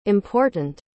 Como pronunciar corretamente important?
A pronúncia de important é /ɪmˈpɔːrtənt/, mas tem um segredinho: no dia a dia, muita gente “engole” o t. Então pode soar algo como impór-n. Isso mesmo, rapidinho e direto.